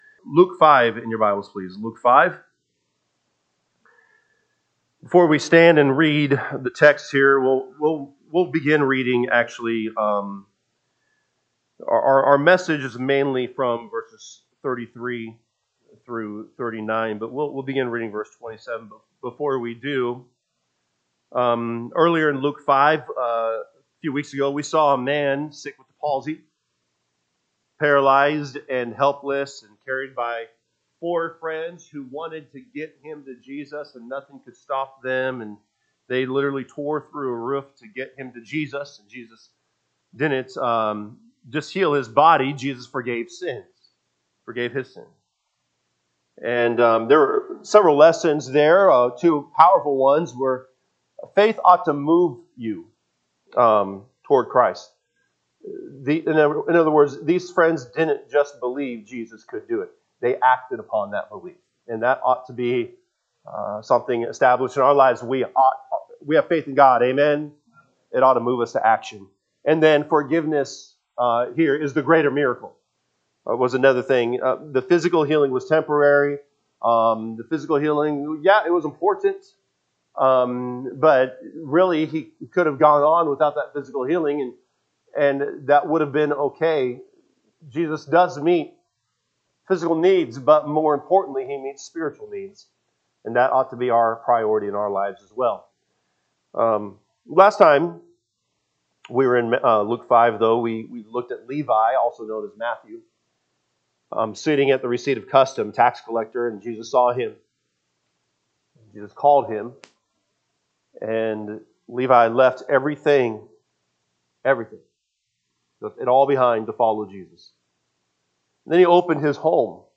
Sunday AM Message